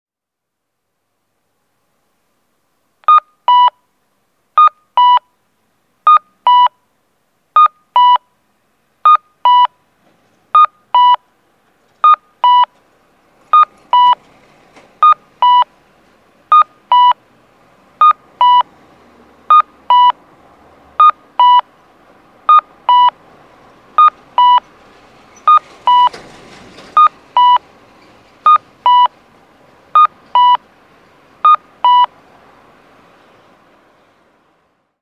交通信号オンライン｜音響信号を録る旅｜山口県の音響信号｜[9045]楊柳橋東詰先交差点
楊柳橋東詰先交差点(山口県周南市)の音響信号を紹介しています。